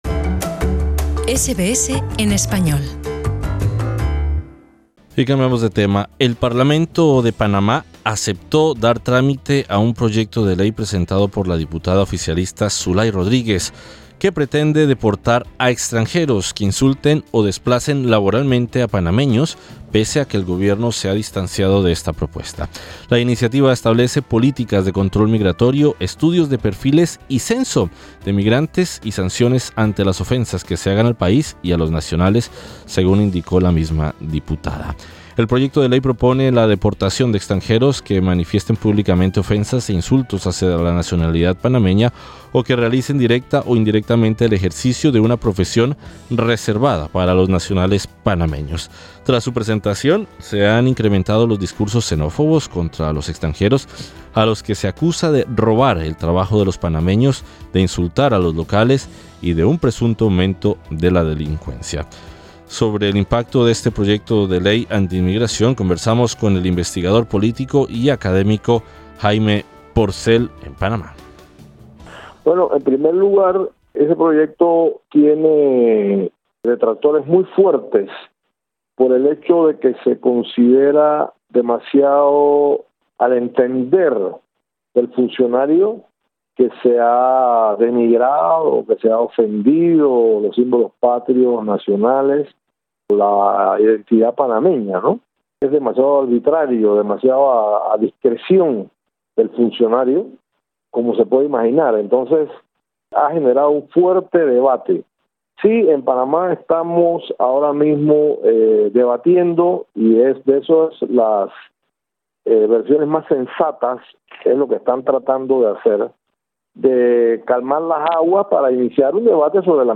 Sobre el impacto de este proyecto de ley anti inmigración, conversamos con el investigador político y sociólogo